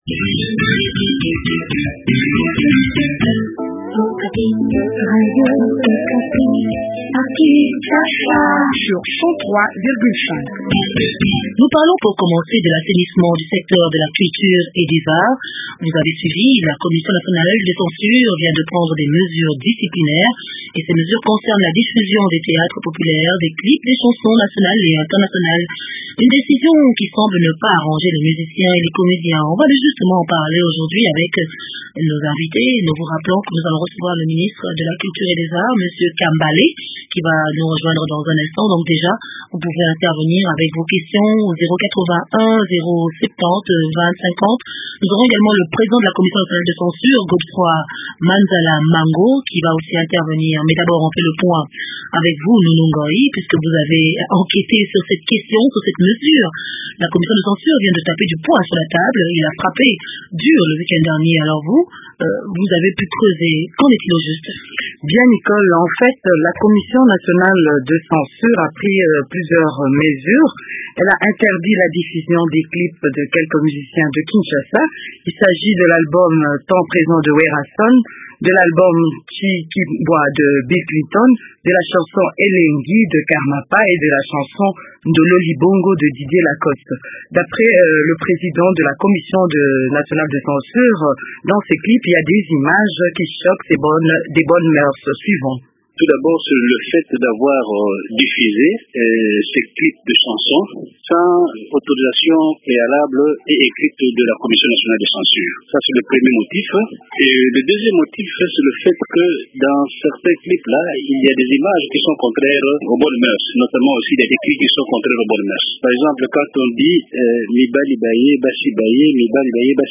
Godefroid Manzala Mango, président de la commission nationale de censure et Esdras Kambale, ministre de la culture et des arts parlent de cette mesure au micro